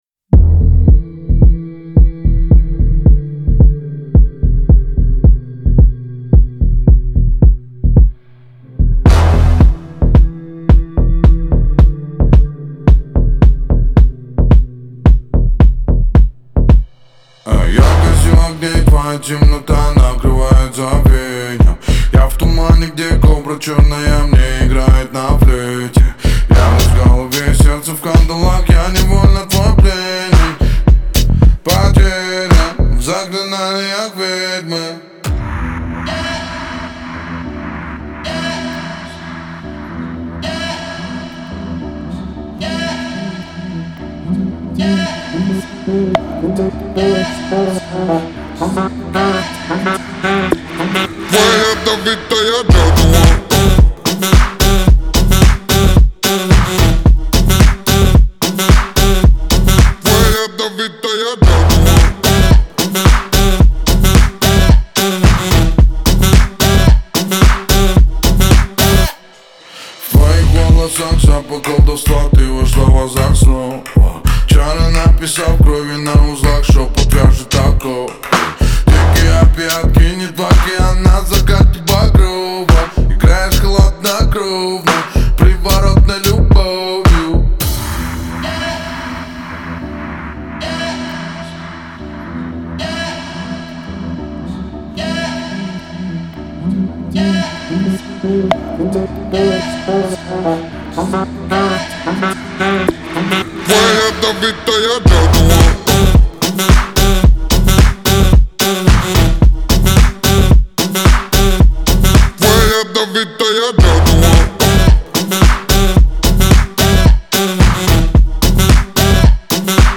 Стиль: Dance / Electronic / House / Pop